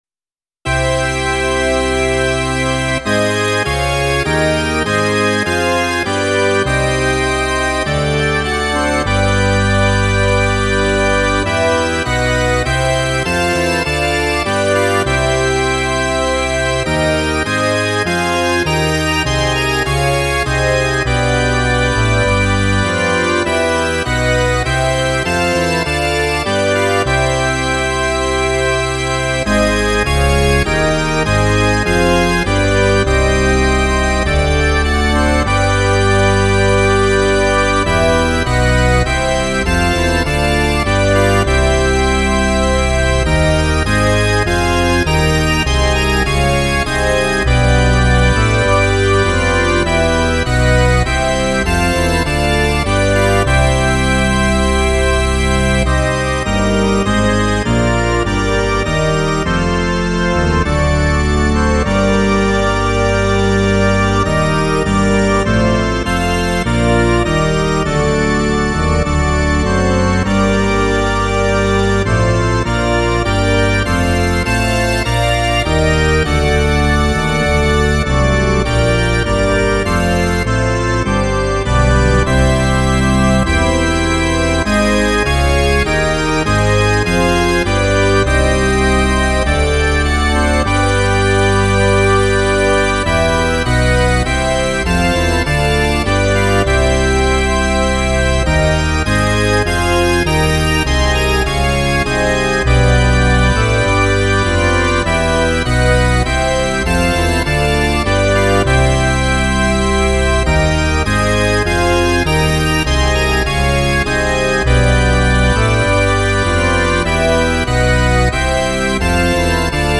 A short piece for the church organ.